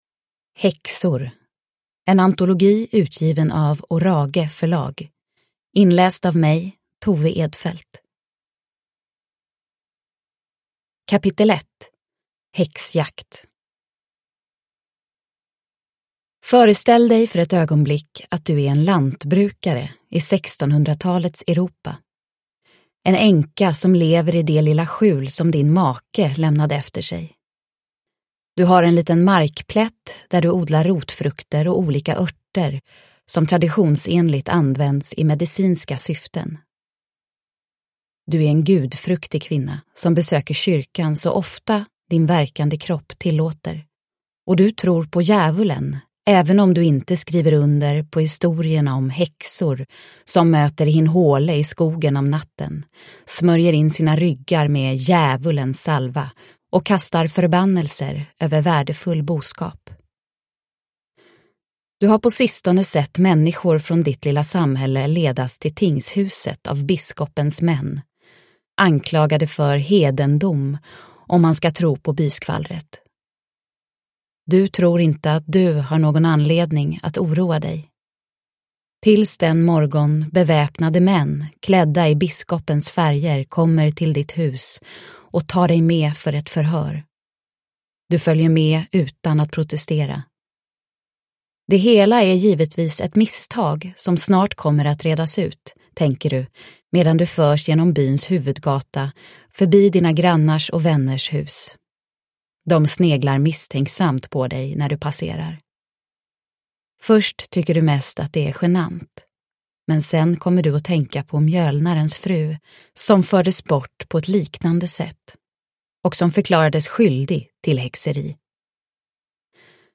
Häxor – Ljudbok – Laddas ner